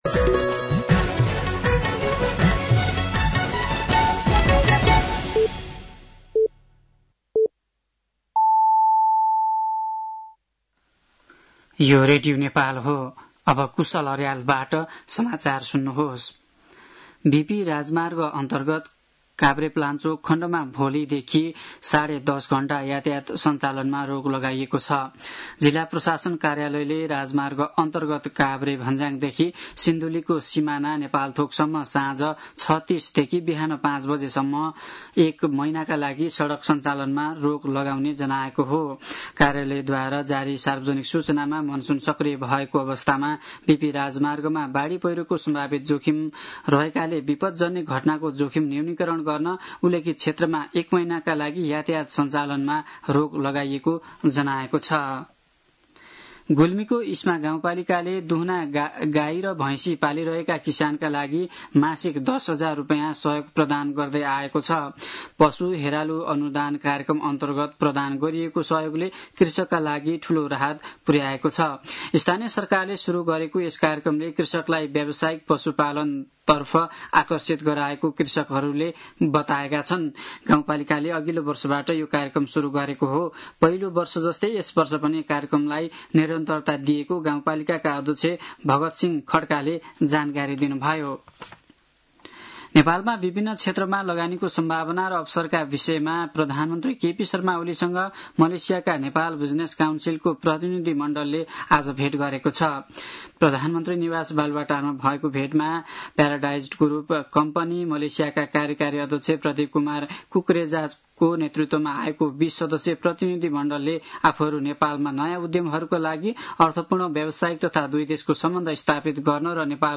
साँझ ५ बजेको नेपाली समाचार : २१ असार , २०८२
5.-pm-nepali-news-1-1.mp3